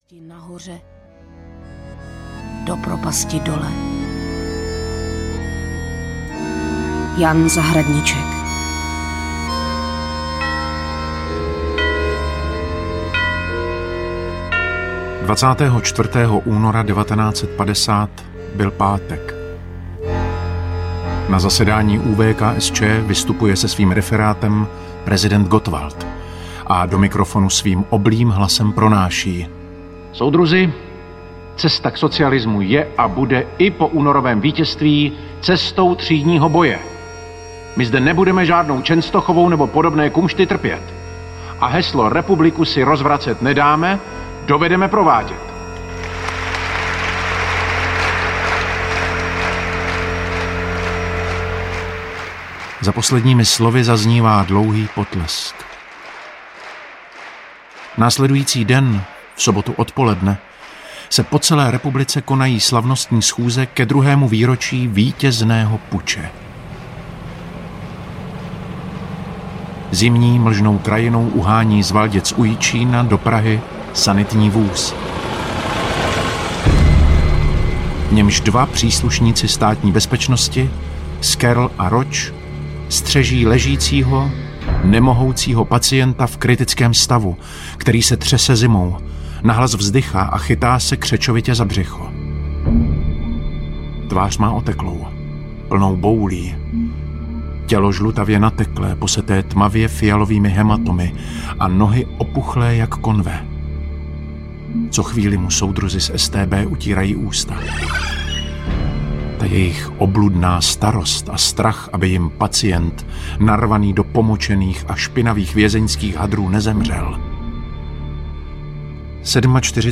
Jako bychom dnes zemřít měli audiokniha
Ukázka z knihy